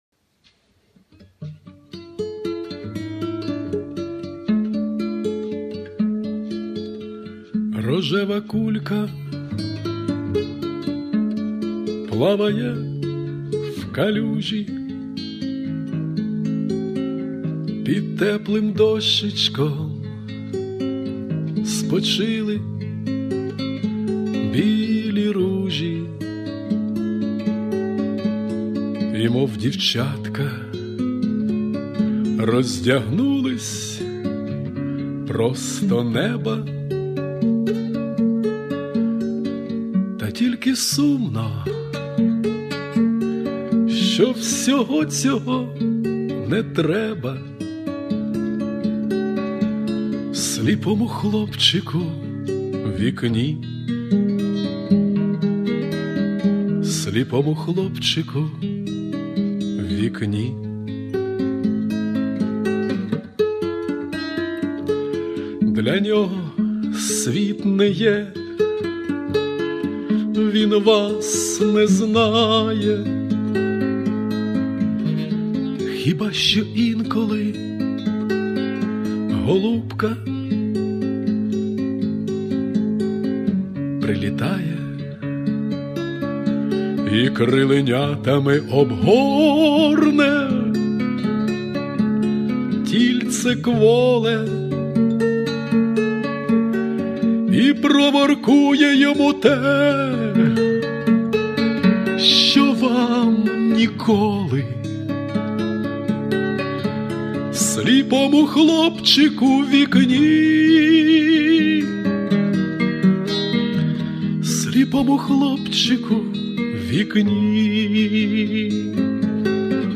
Авторська пісня